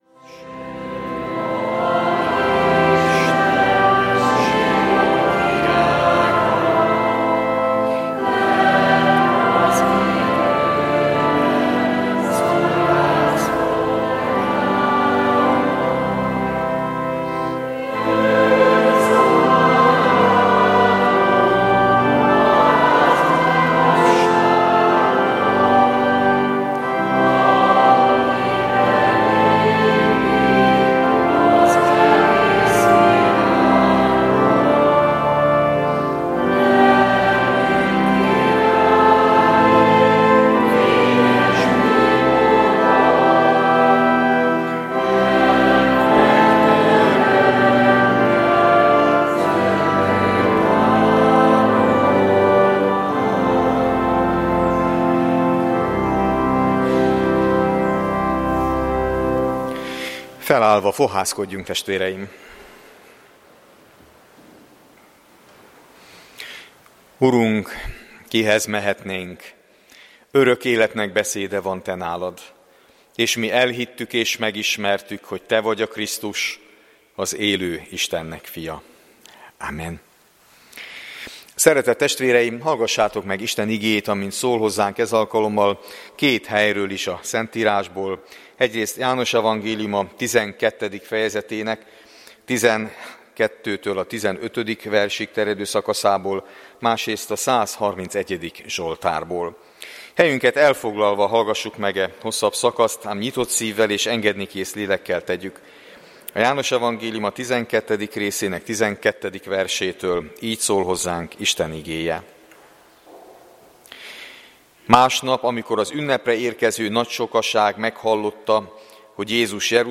Virágvasárnapi istentisztelet